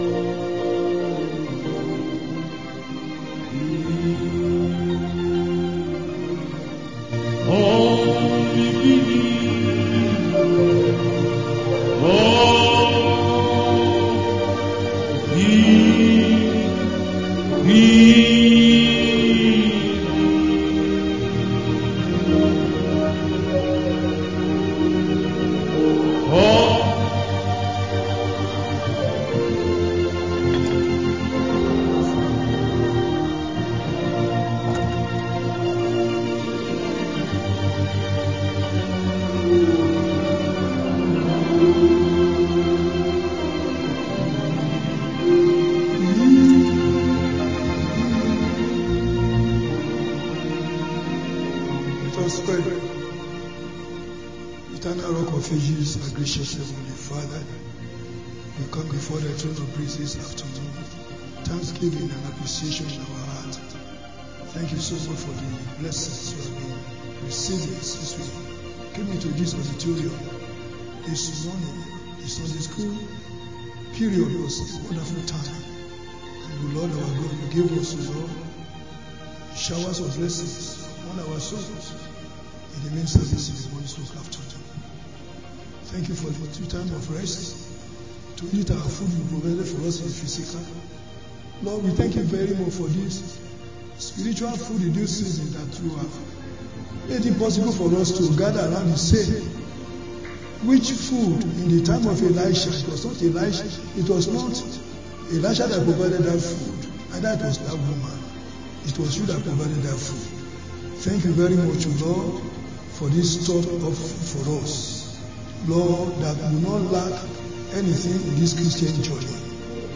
Sunday Afternoon Service 23/02/25_Evang